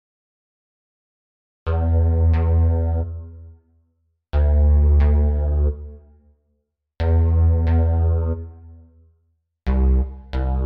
描述：巨大的EDM合成器下降
Tag: 128 bpm House Loops Synth Loops 2.53 MB wav Key : F